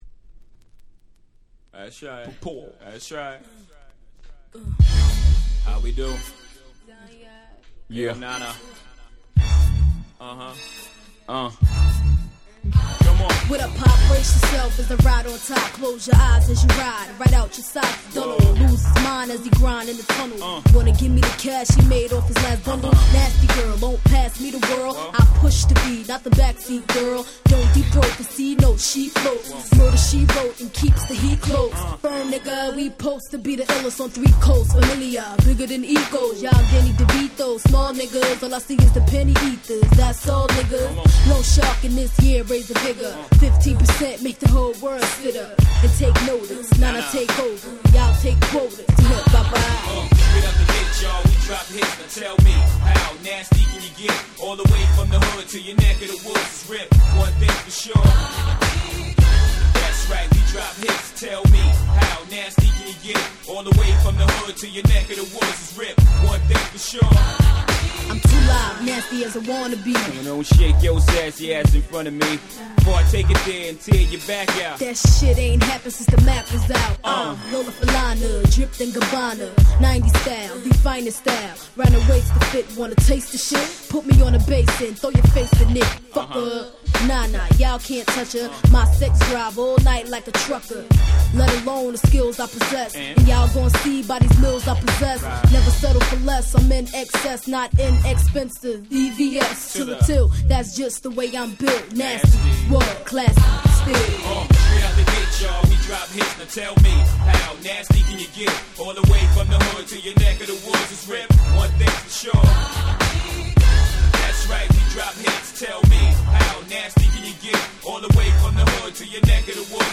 96' Super Hit Hip Hop !!
Boom Bap ブーンバップ